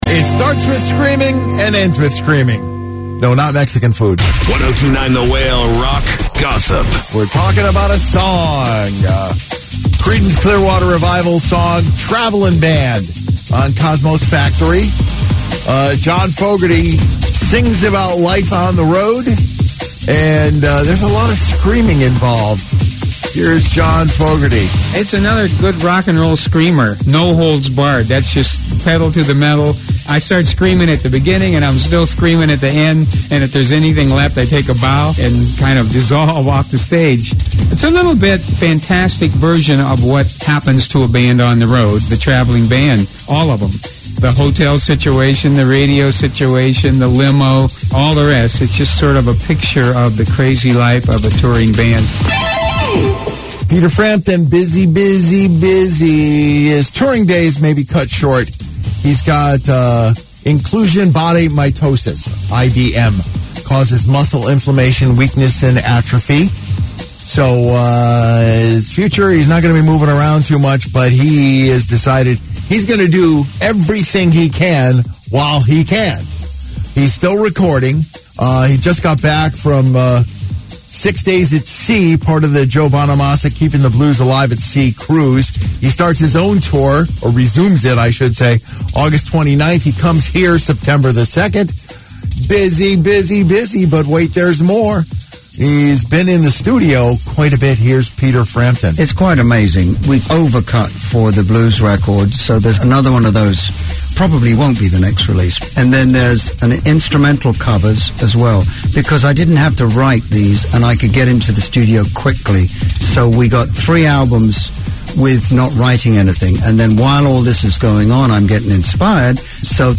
Last September, he and his band went into the studio and cut enough songs for three more albums. Here’s Peter Frampton on the future albums he has planned: